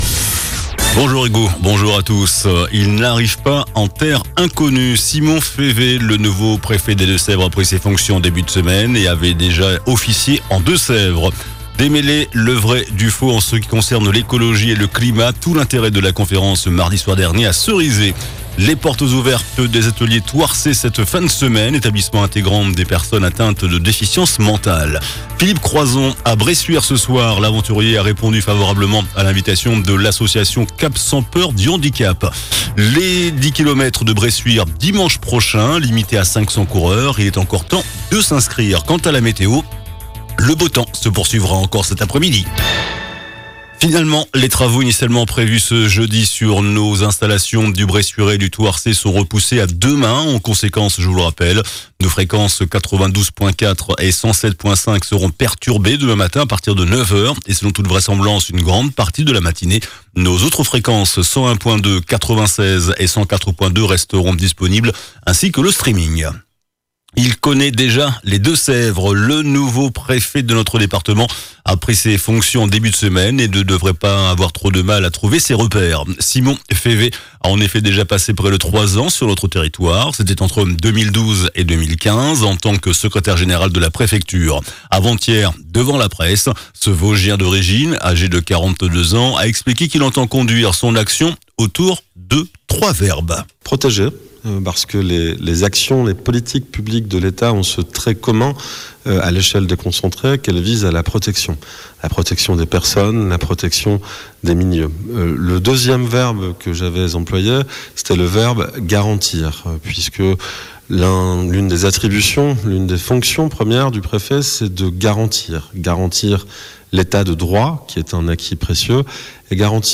JOURNAL DU JEUDI 10 AVRIL ( MIDI )